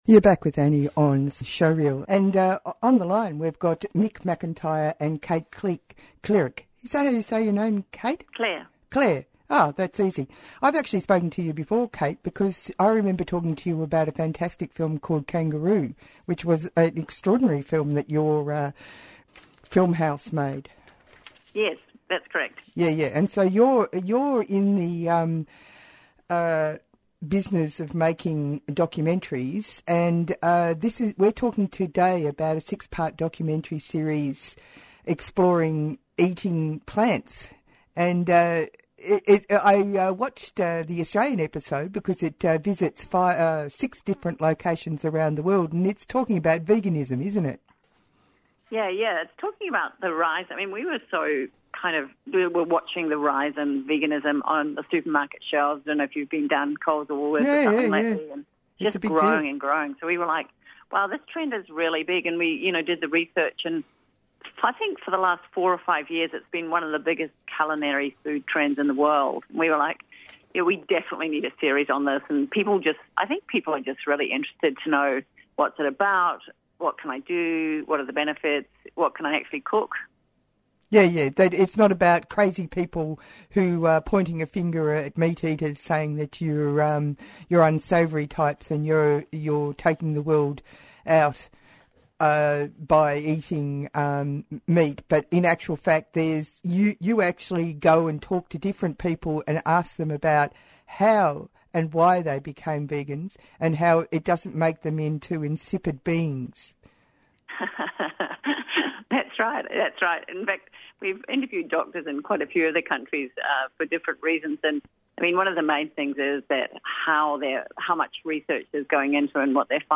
Presenter